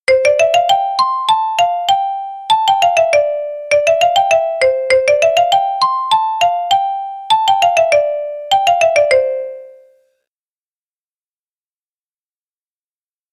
Xylophone
Famille : percussions
Descriptif : cet instrument, posé sur pieds, est composé de deux rangées de lames en bois sur lesquelles on frappe avec des mailloches.
Le son en est également plus sec.